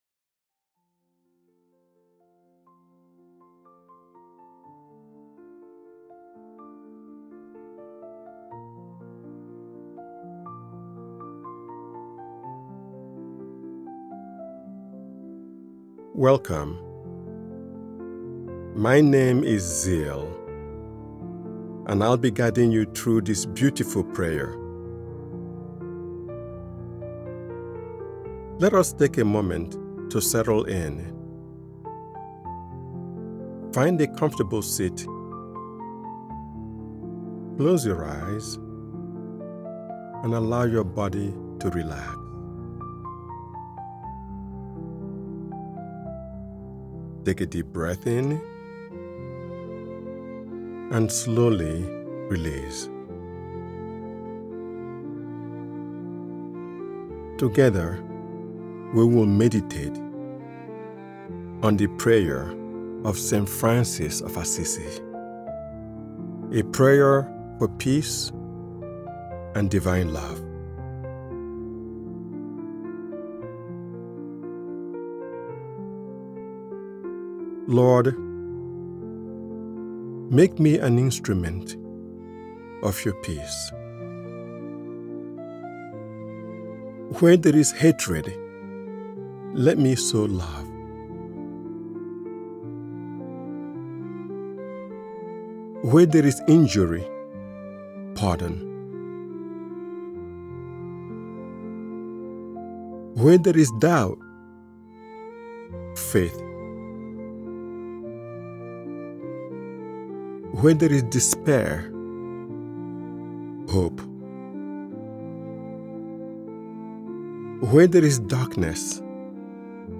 Prayer of St. Francis: A Meditation on Peace and Compassion is a reflection-centered guided meditation designed to help you embody the timeless spirit of kindness, humility, surrender, and harmony found in the beloved Prayer of St. Francis.
Prayer-of-St.Francis-1.mp3